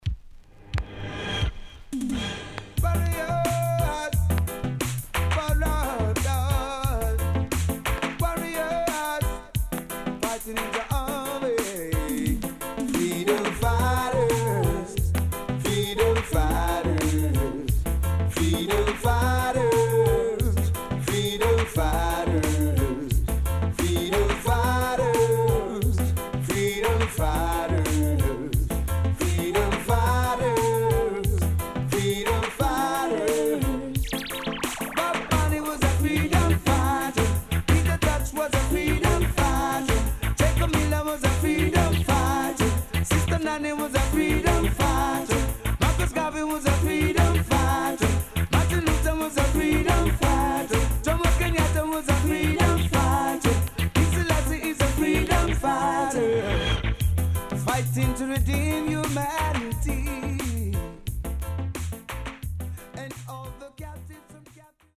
HOME > LP [DANCEHALL]
SIDE B:少しチリノイズ、プチノイズ入ります。